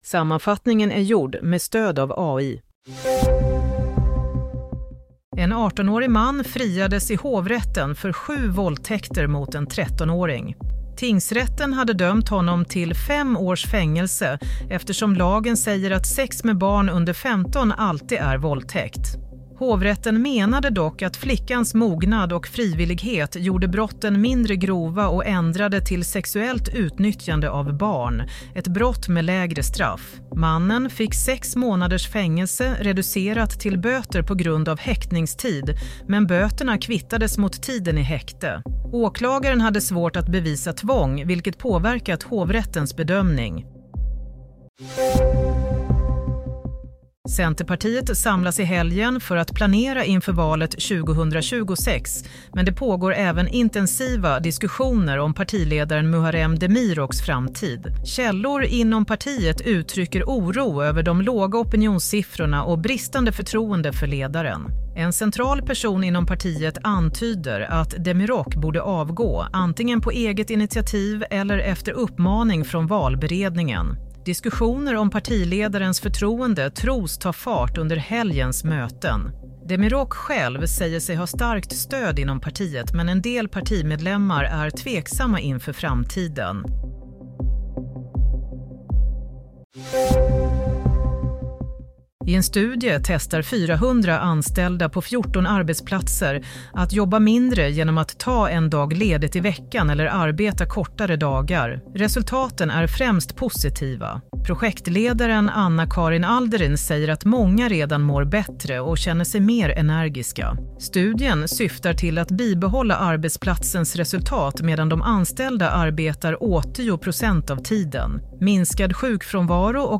Nyhetssammanfattning – 31 januari 16:00
Sammanfattningen av följande nyheter är gjord med stöd av AI.